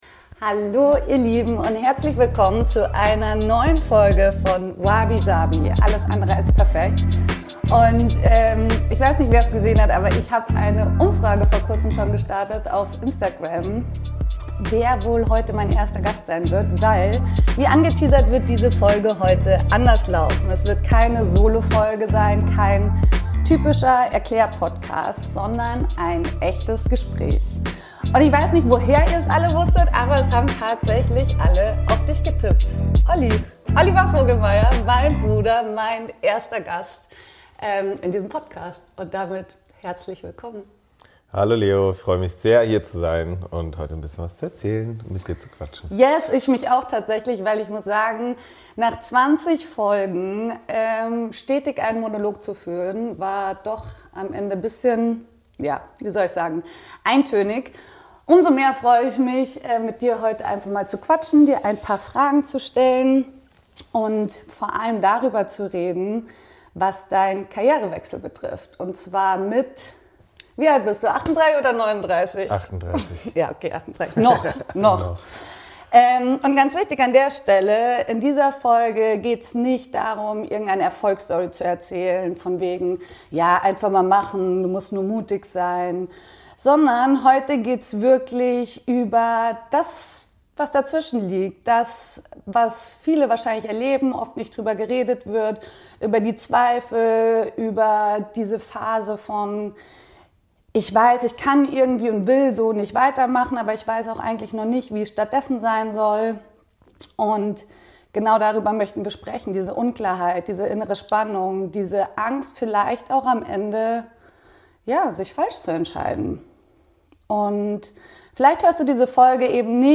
Ein ehrliches Gespräch über das Dazwischen, Unsicherheit aushalten und Schritte gehen, ohne den perfekten Plan zu haben.